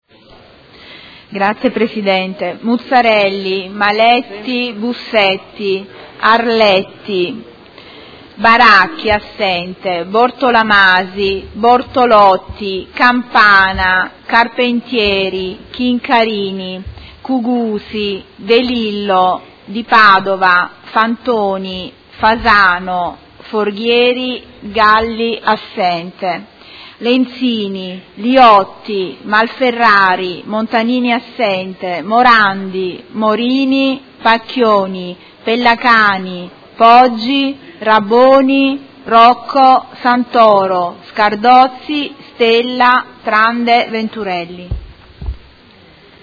Seduta del 20/12/2018. Appello
Segretario Generale